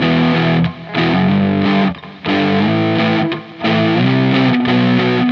摇滚吉他循环
描述：没有什么特别的，"power chords" E、G、A、C和B。用吉他和Gutar Rig 5录制。
Tag: 90 bpm Rock Loops Guitar Electric Loops 918.80 KB wav Key : E